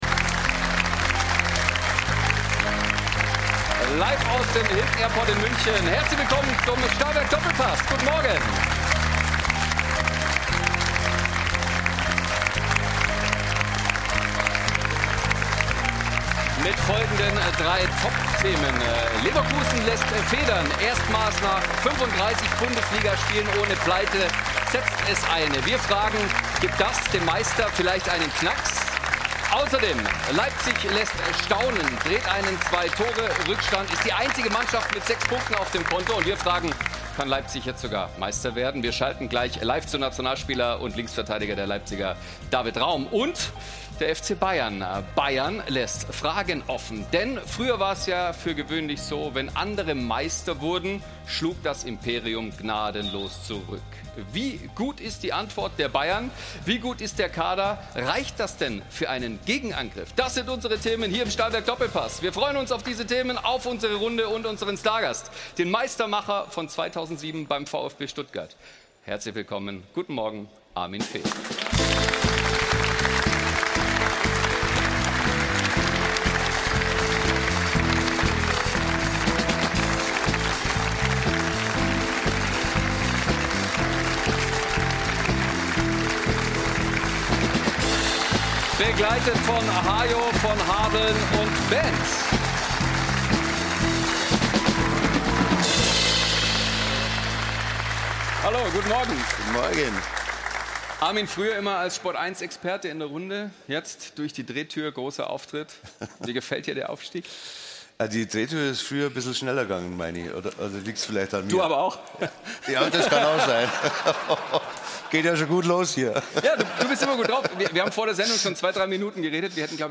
Zugeschaltet ist außerdem DFB-Geschäftsführer Sport Andreas Rettig. In der neuen Folge des Doppelpass diskutieren sie unter anderem über die Themen: 0:00 – Ende der Serie: Bayer verliert gegen Leipzig 29:40 - Leverkusen in der Champions League – und der neue Modus 59:55 - Ohne Guirassy – wie stark ist Dortmund? 01:10:15 - Leipzigs David Raum: Eindrücke aus dem Topspiel in Leverkusen Der Doppelpass jetzt auch als Newsletter: Hier anmelden!